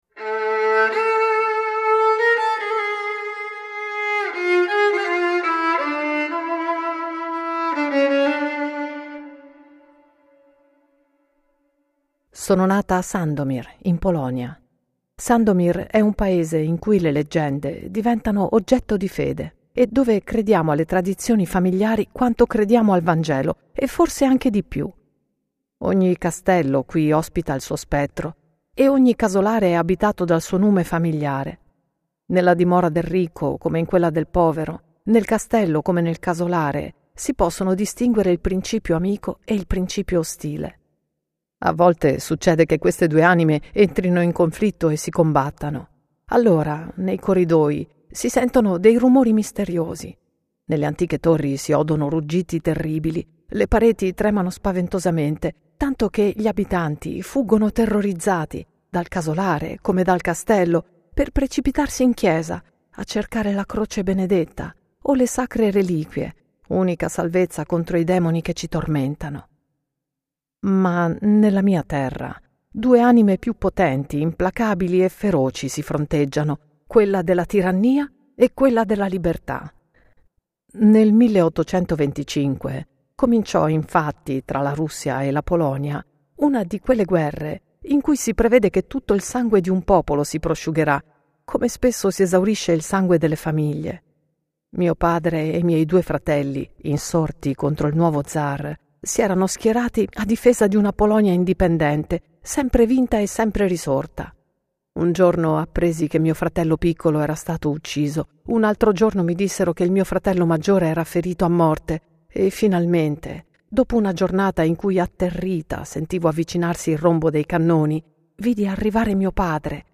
Audiolibro La storia della dama pallida - Alexandre Dumas - Recitar Leggendo audiolibri